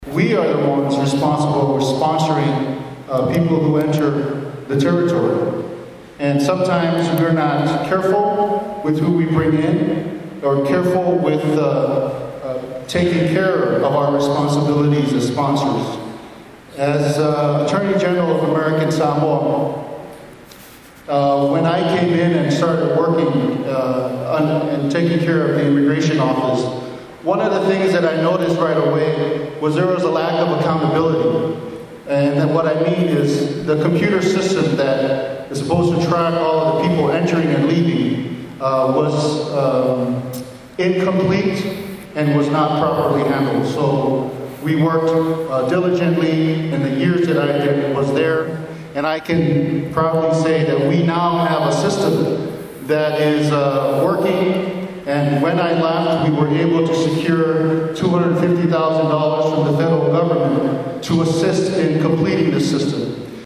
That’s one of the questions at the ASCC Gubernatorial Forum last week.